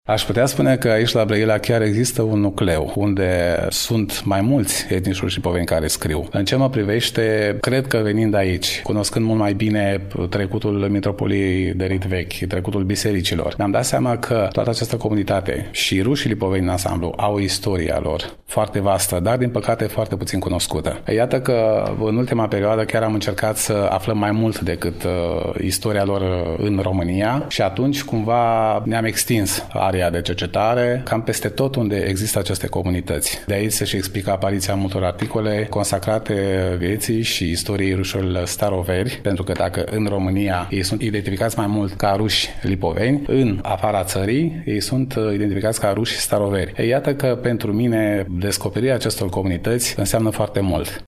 În emisiunea de astăzi poposim acasă la rușii lipoveni din Brăila, mai exact în incinta sediului cultural al Comunității din cartierul Pisc, de pe strada Alexandru Davila, Numărul 13.